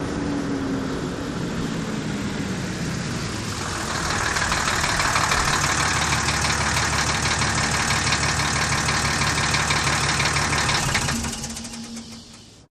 Truck, Hino Diesel, Pull Up, Shut Off